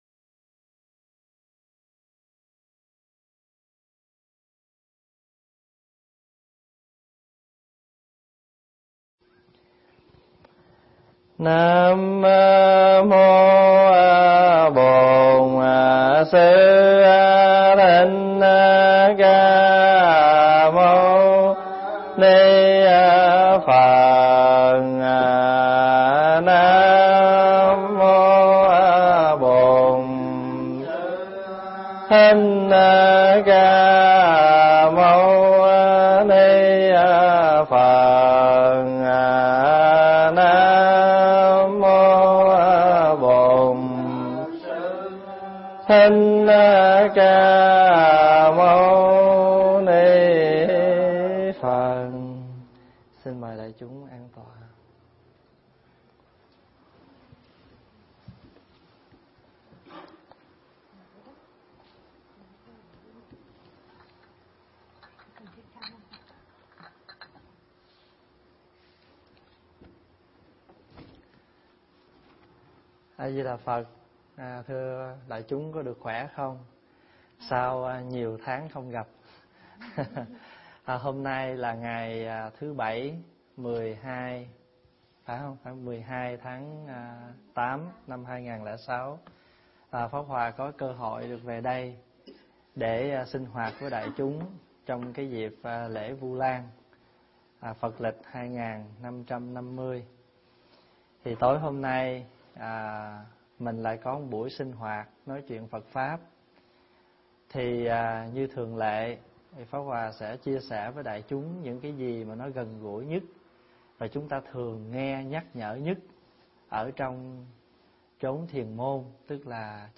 thuyết giảng tại Chùa Hải Đức, Lễ vu lan